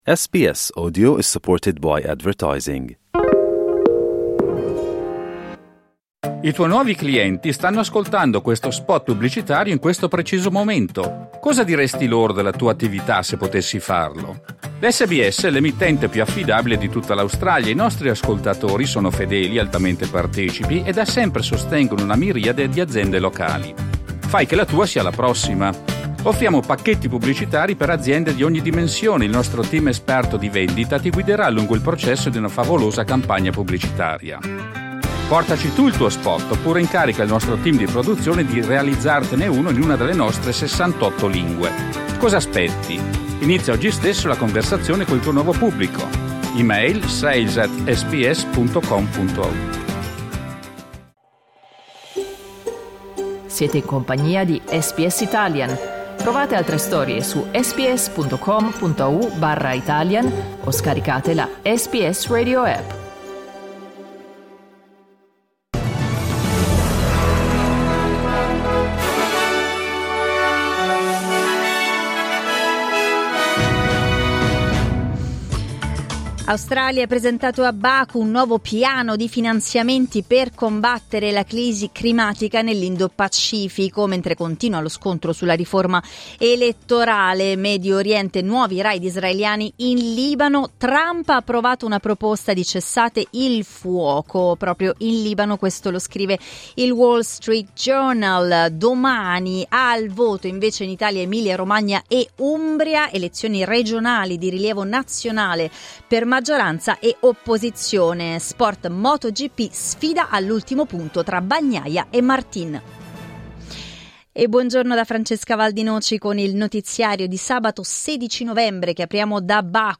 … continue reading 1 Giornale radio sabato 16 novembre 2024 12:46 Play Pause 1h ago 12:46 Play Pause बाद में चलाएं बाद में चलाएं सूचियाँ पसंद पसंद 12:46 Il notiziario di SBS in italiano.